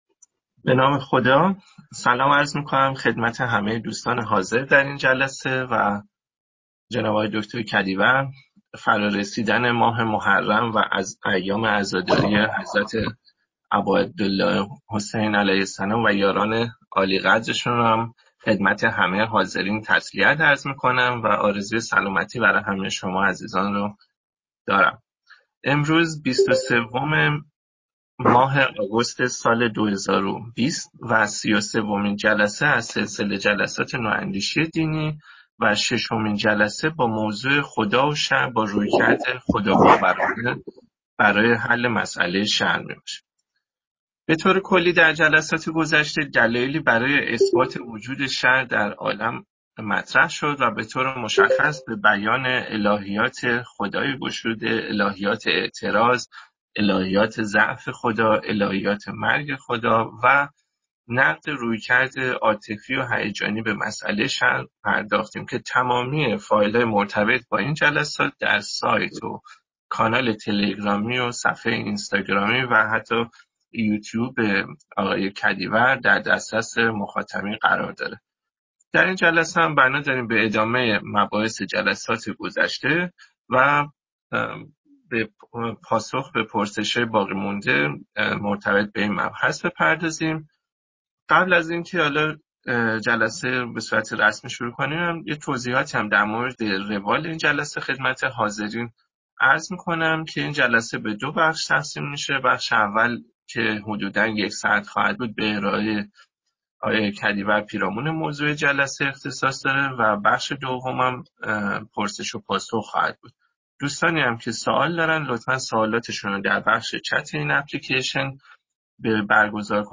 دروس عمومی / خدا و شرّ / خدا و شرّ -۶